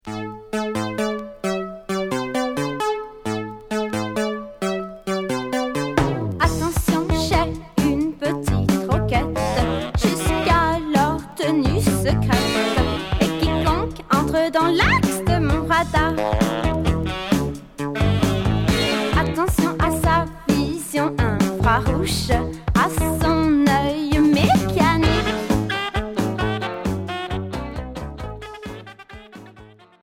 New wave Deuxième 45t retour à l'accueil